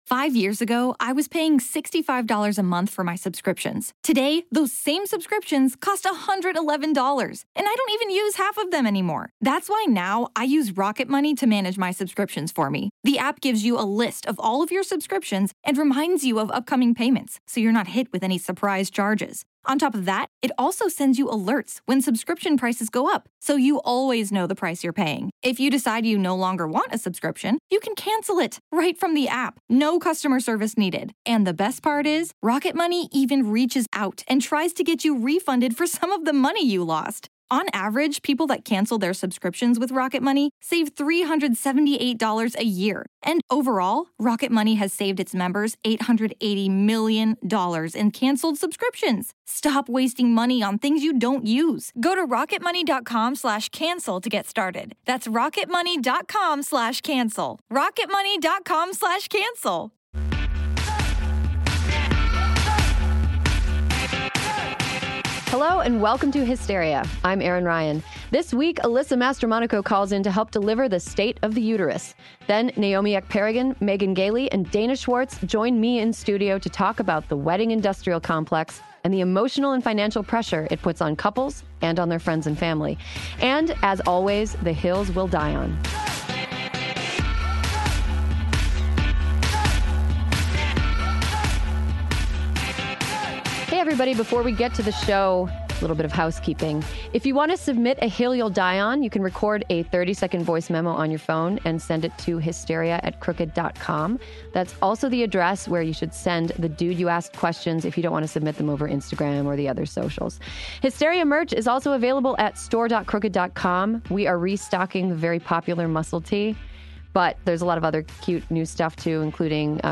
join in studio to discuss the wedding industrial complex